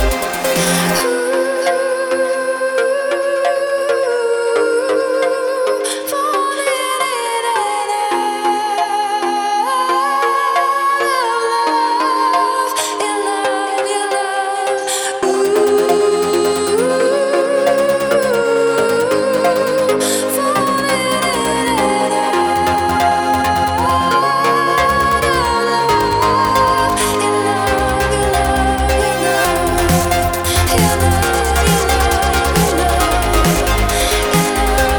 Жанр: Транс